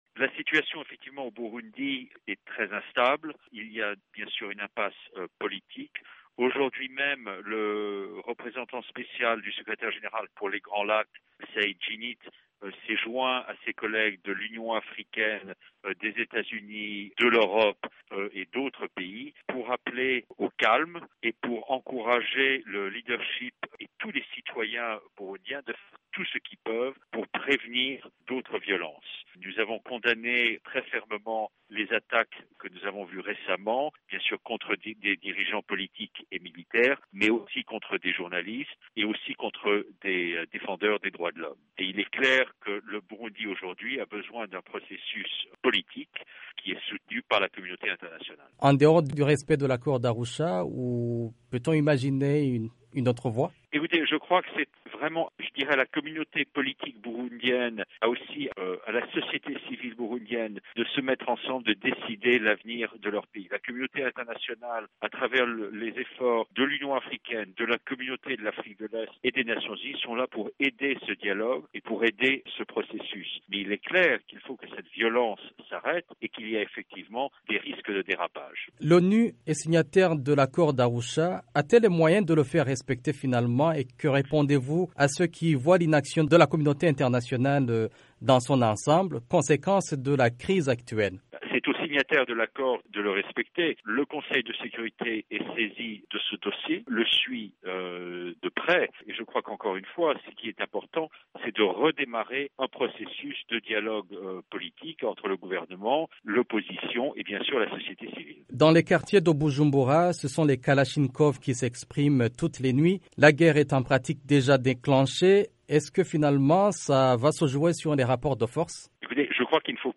Dans une interview à VOA Afrique, Stephane Dujarric, porte-parole du Secrétaire général de l'ONU, est revenu sur la déclaration des envoyés spéciaux de plusieurs puissances occidentales et des organisations internationales.